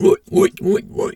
pgs/Assets/Audio/Animal_Impersonations/pig_2_hog_seq_03.wav at master
pig_2_hog_seq_03.wav